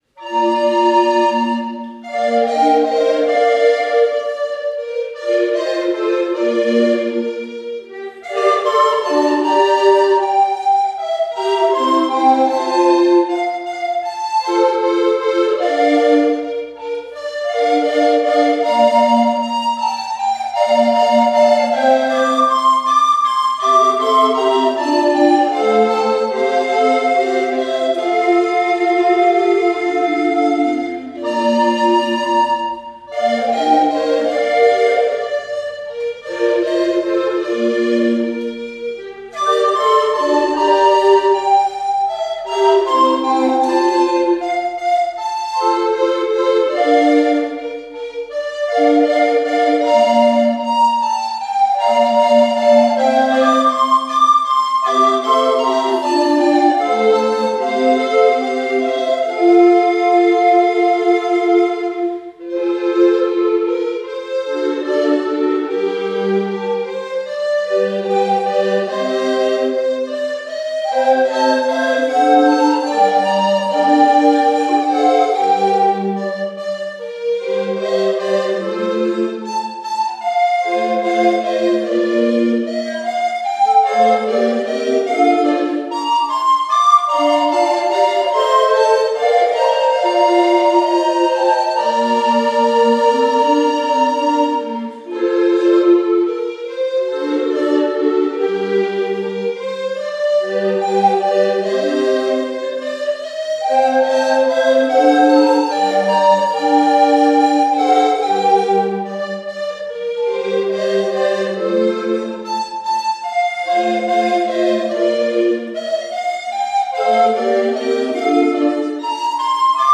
„The Fairy Queen“ von Purcell, als Blockflötenquartett bearbeitet, lag plötzlich in meinen Händen.
an einem recht heißen Samstag unsere Blockflötenquartette für das Klassenspiel und auch die Fairy Queen professionell aufnehmen zu lassen.